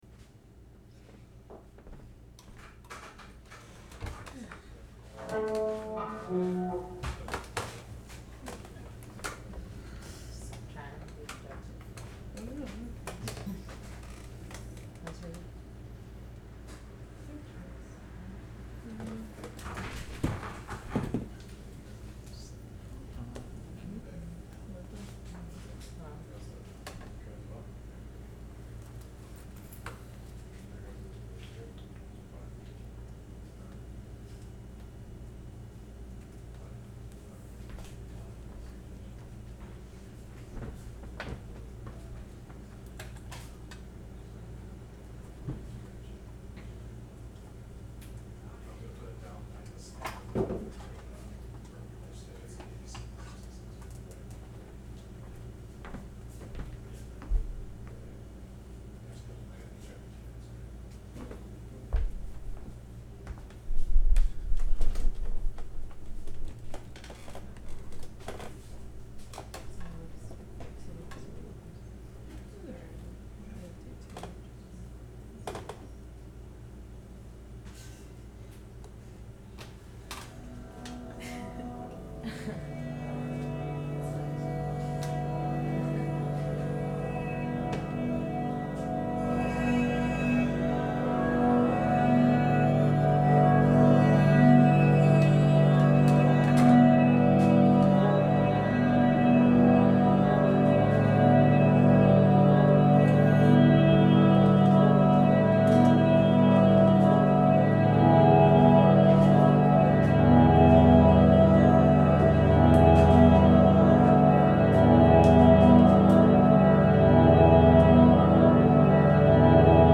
Noise artist